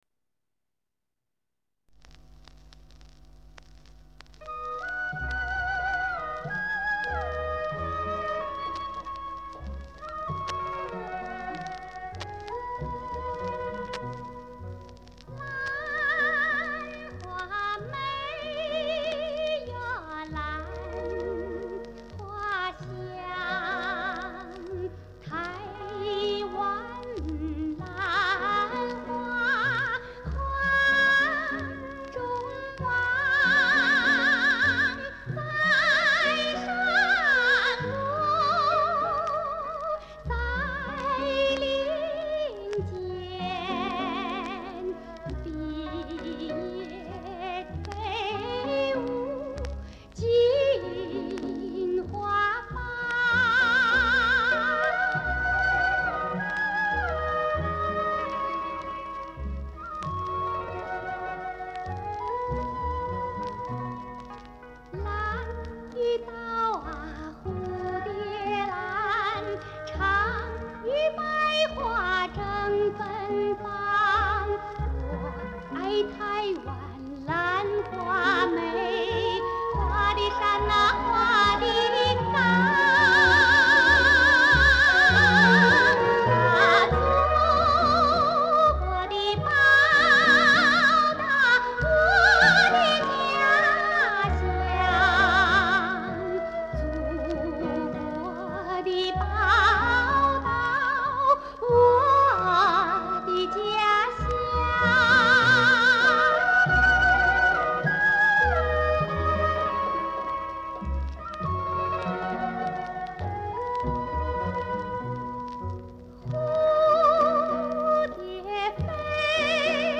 那优美的旋律令人难忘